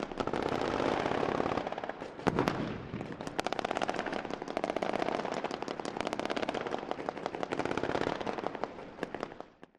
Battle simulation with rapid fire weapons and jet and helicopter flybys. Weapons, Gunfire Bombs, War Battle, Military